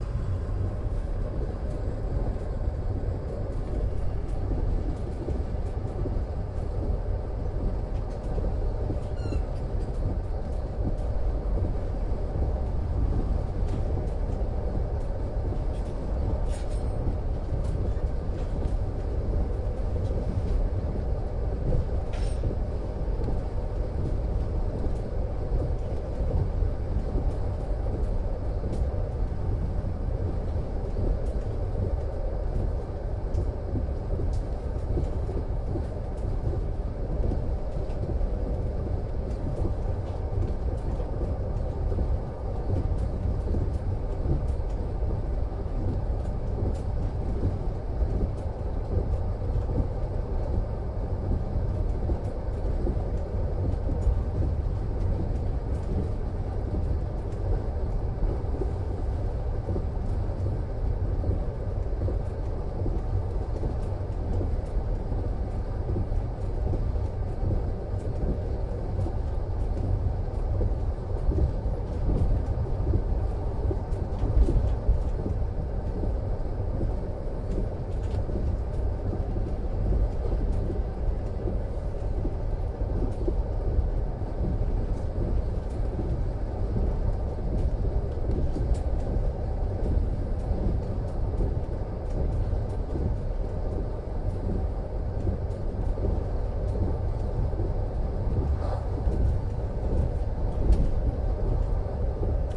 火车" 夜间客运车皮3
描述：晚上坐客运马车。指挥家铮铮作响的菜肴。录制于2013年3月30日。XYstereo.Tascam DR40, deadcat
标签： 铁路 夜间 火车 隆隆声 旅游 客运 导体 餐具 噪音 铮铮 货车 客货车
声道立体声